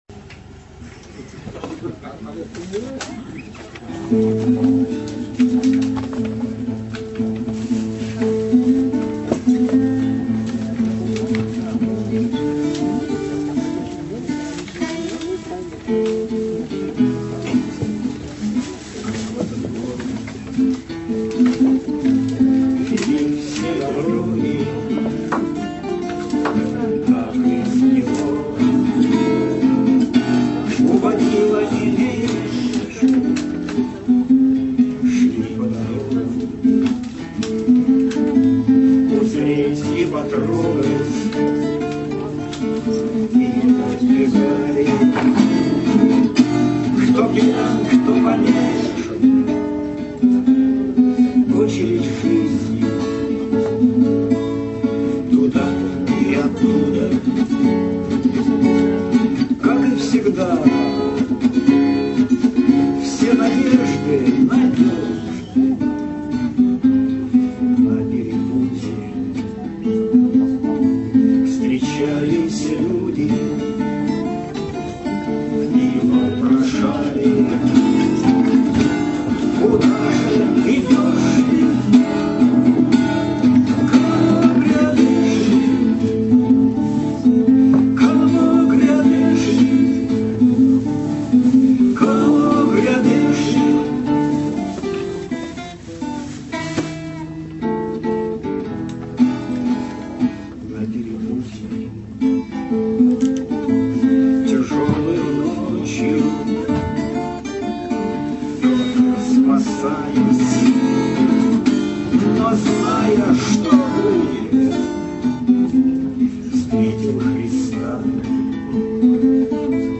острота не пропала, т.к. в песне больше двух аккордов и витиеватая аранжировка, надо куда-нибудь подсмотреть, так что запись отнюдь не лишняя...